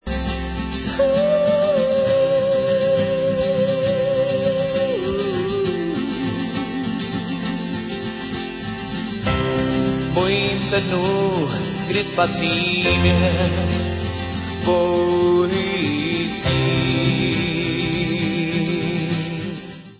skvělými pomalými hity